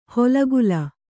♪ holagula